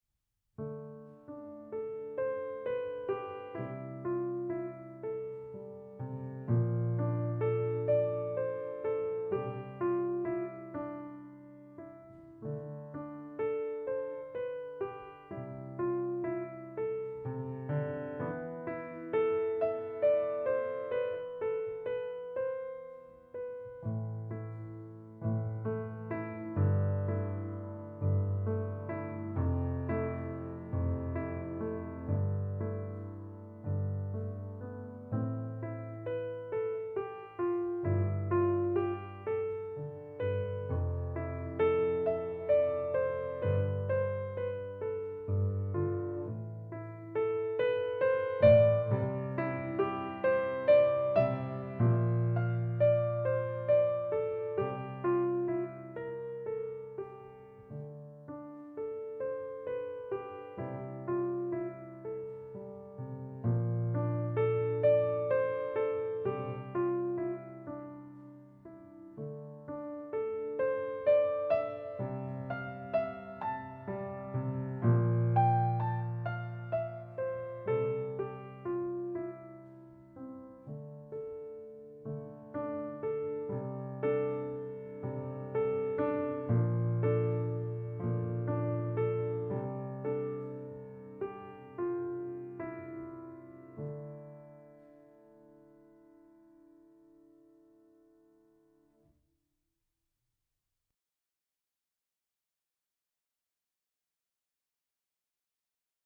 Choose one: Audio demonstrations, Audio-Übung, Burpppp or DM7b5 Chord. Audio demonstrations